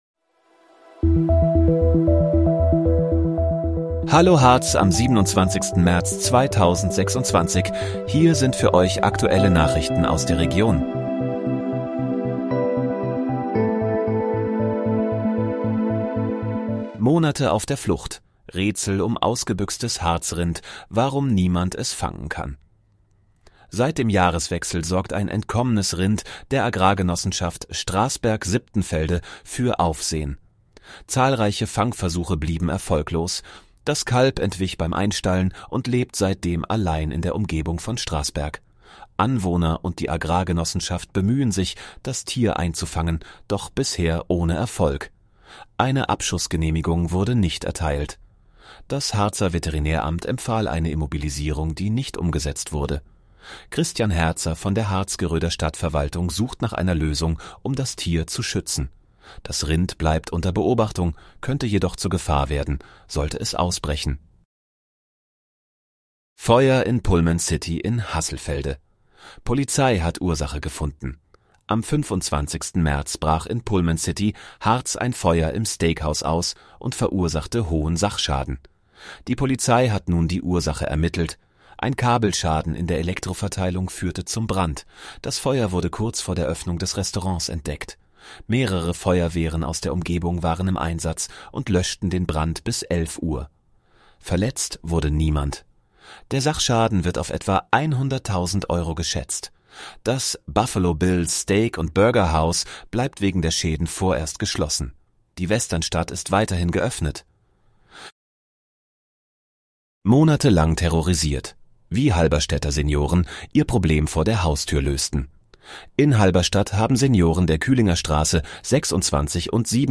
Hallo, Harz: Aktuelle Nachrichten vom 27.03.2026, erstellt mit KI-Unterstützung